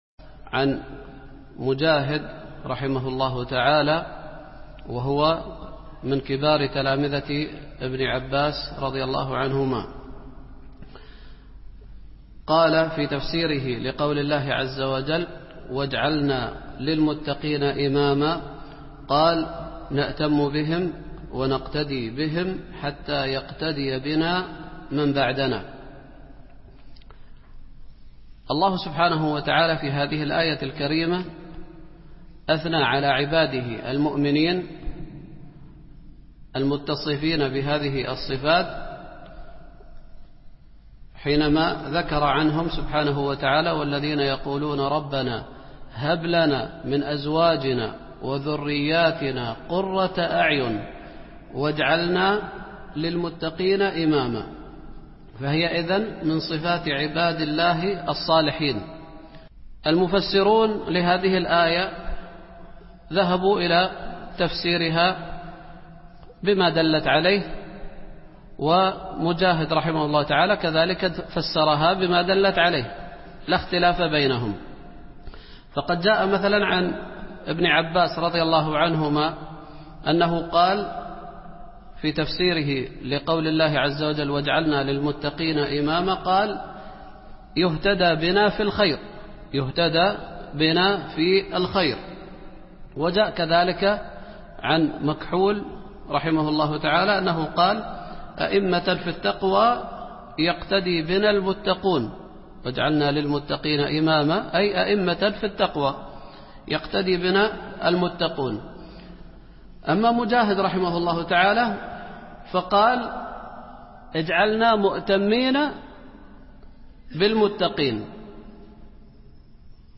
التفسير